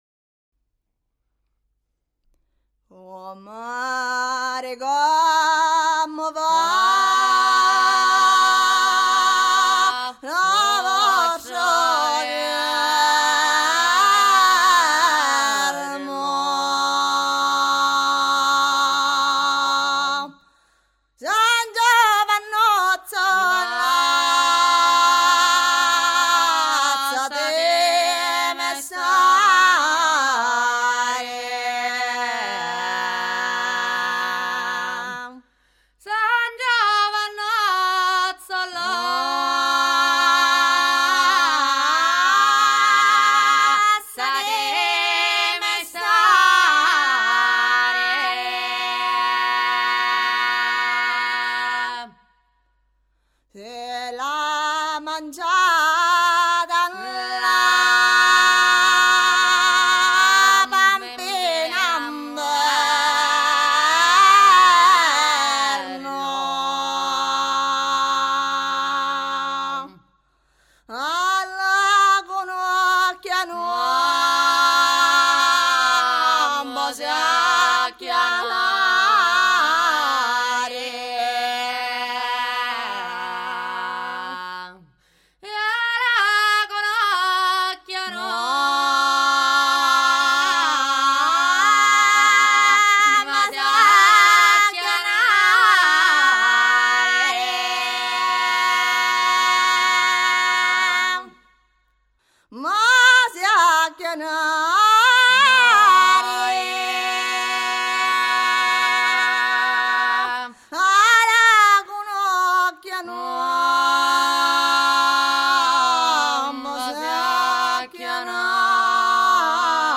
Musica Folk